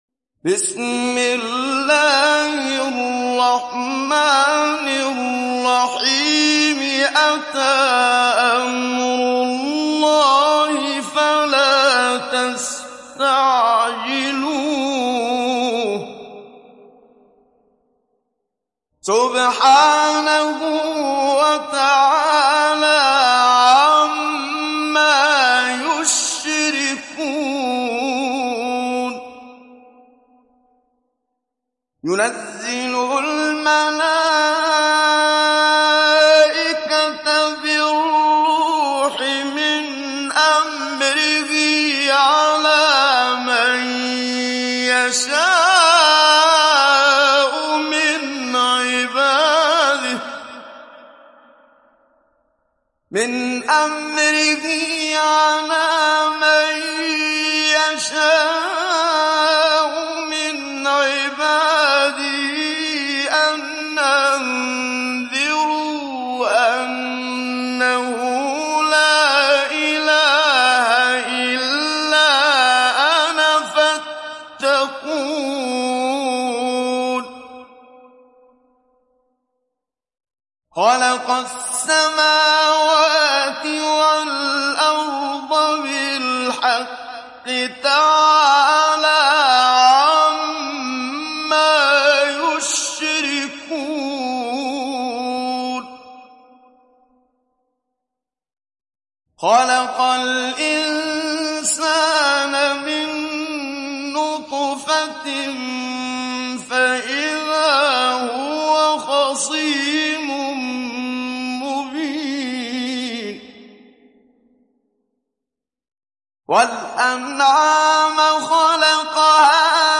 تحميل سورة النحل mp3 بصوت محمد صديق المنشاوي مجود برواية حفص عن عاصم, تحميل استماع القرآن الكريم على الجوال mp3 كاملا بروابط مباشرة وسريعة
تحميل سورة النحل محمد صديق المنشاوي مجود